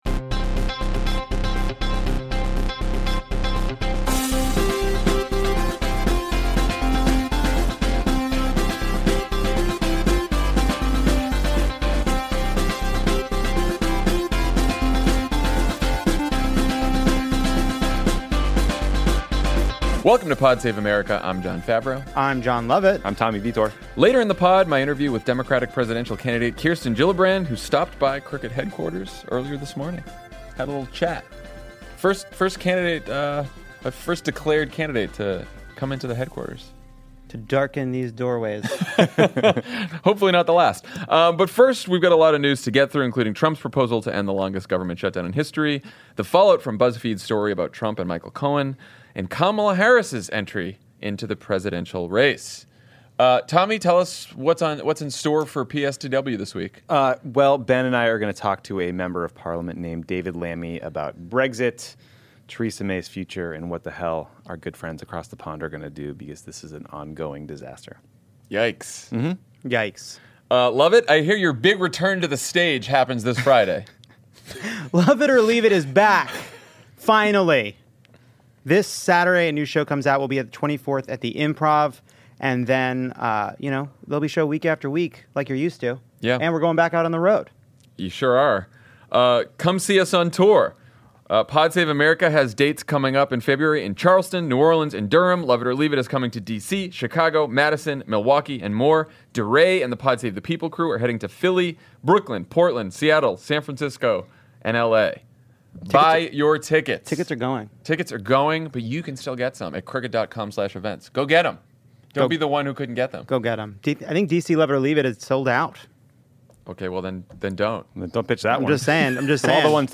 Trump offers the Democrats a deal they can’t accept, Buzzfeed defends its story about Trump suborning perjury, and Kamala Harris announces her candidacy for president. Then Senator Kirsten Gillibrand talks to Jon Favreau about why she’s running for president, and what she’ll do if she wins.